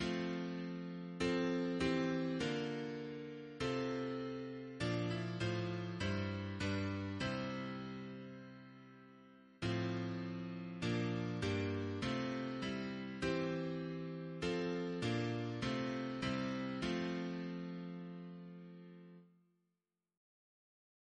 Double chant in F Composer: William Knyvett (1779-1856) Note: after Handel Reference psalters: ACB: 38; ACP: 7; PP/SNCB: 31; RSCM: 85